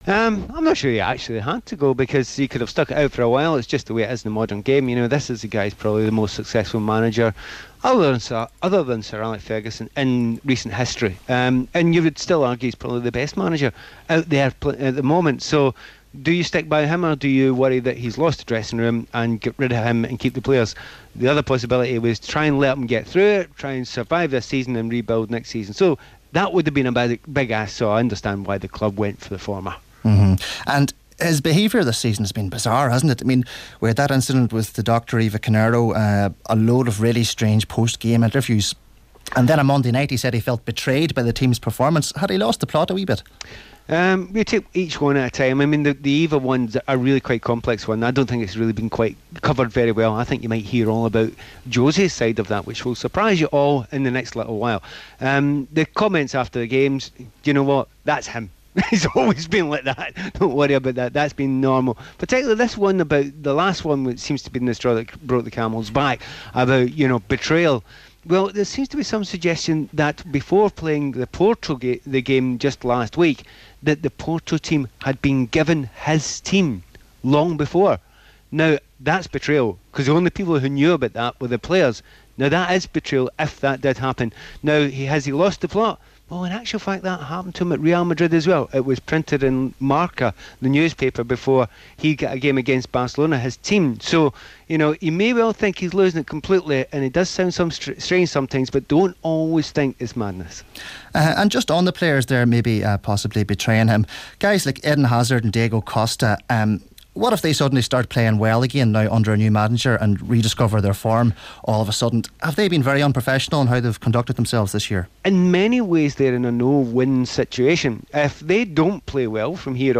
Former Chelsea player and BBC pundit Pat Nevin on the fall out from Jose Mourinho's sacking as Chelsea manager.